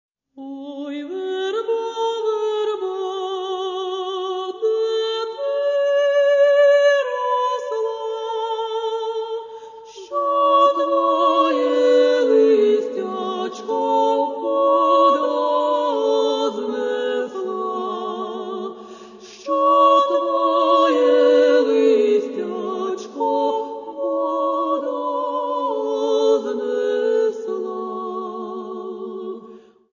музика: народна пісня